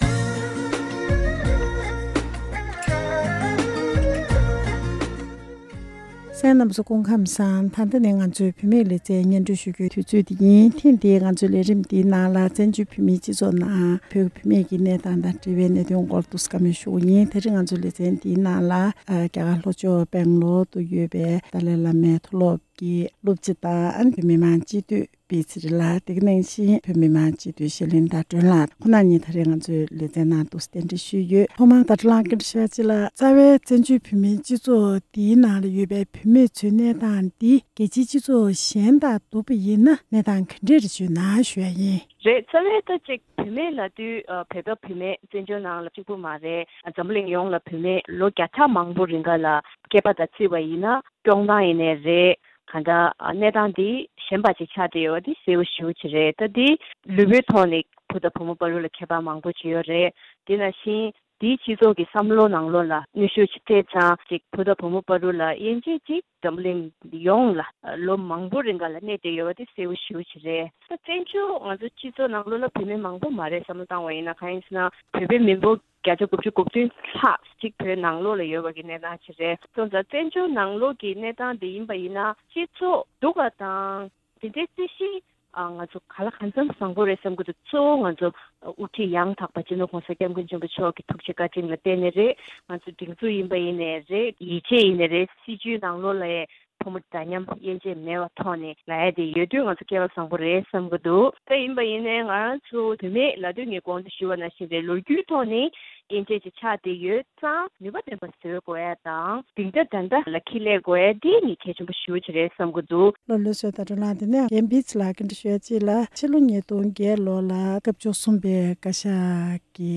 བཙན་བྱོལ་བོད་མིའི་སྤྱི་ཚོགས་ནང་བུད་མེད་ཀྱི་གནས་སྟངས། སྤྱི་འཐུས་བི་ཚེ་རིང་ལགས་དང་སྤྱི་འཐུས་ཤར་གླིང་ཟླ་སྒྲོན་ལགས།
སྒྲ་ལྡན་གསར་འགྱུར།